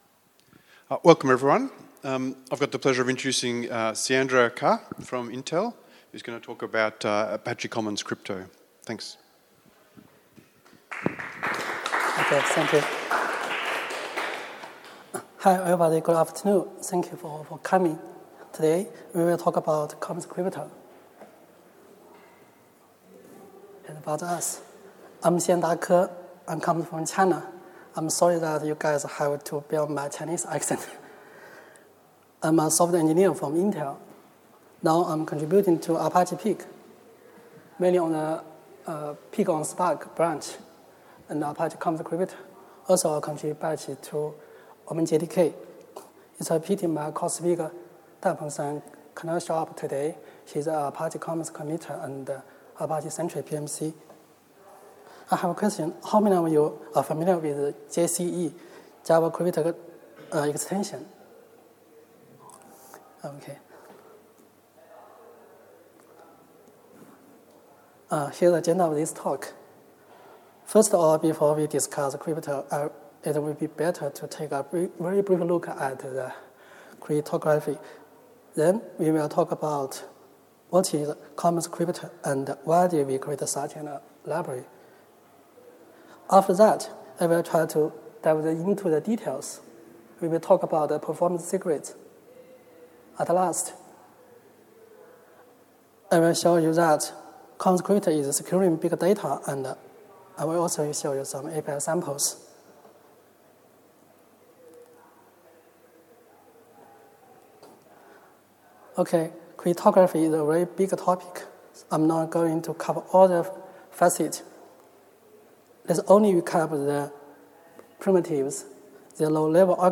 ApacheCon Seville 2016